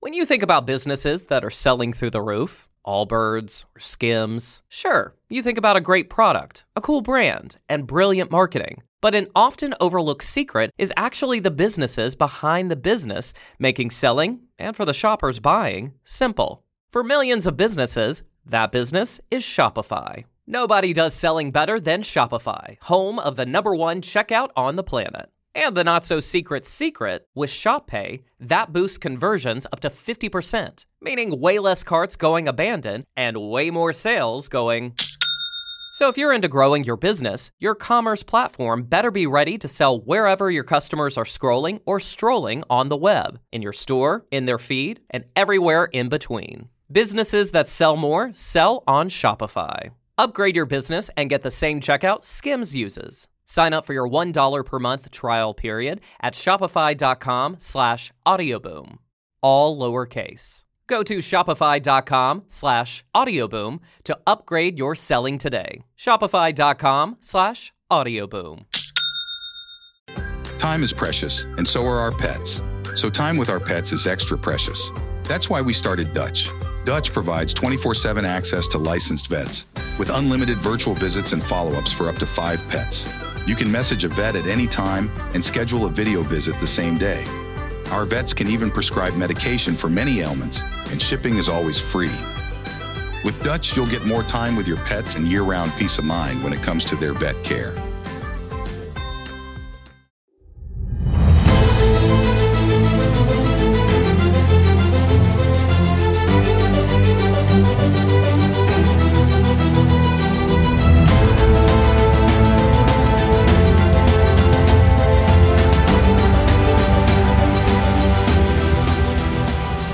Episode 56 - Heal It By Navigating Workplace Conflict - An enlightening conversation